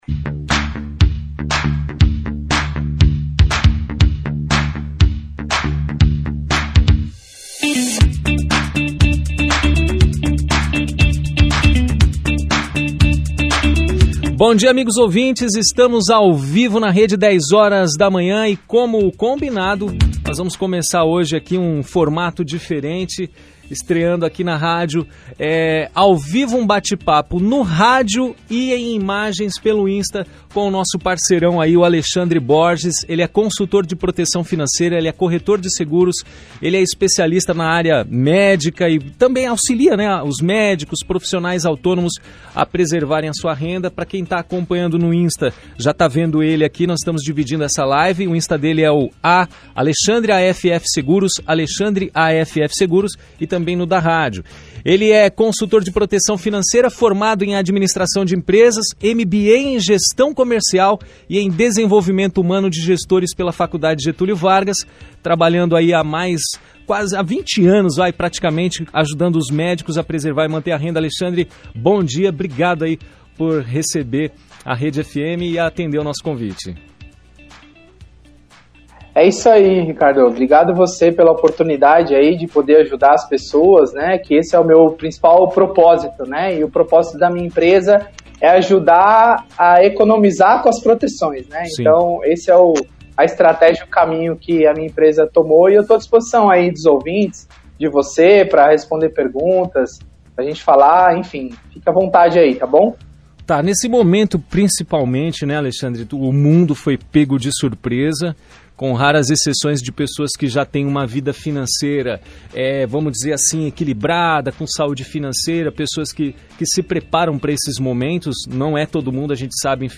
Bate papo